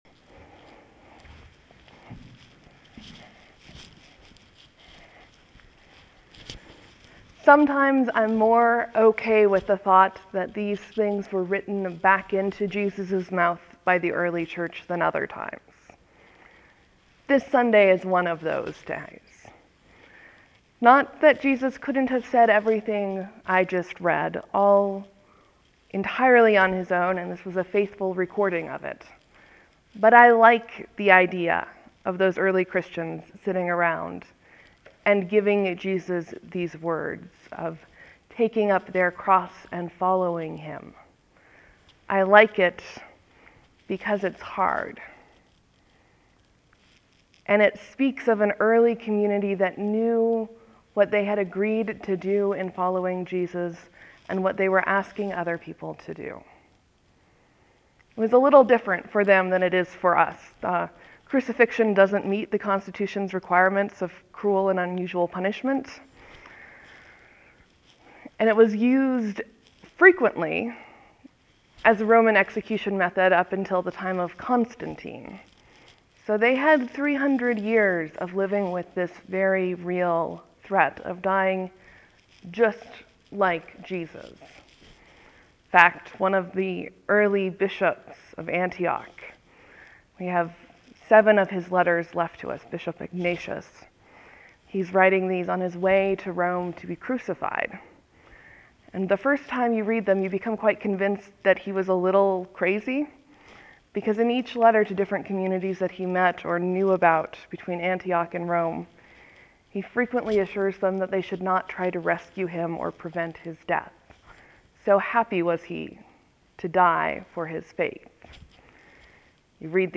(There will be a few moments of silence before the sermon starts. Thank you for your patience.)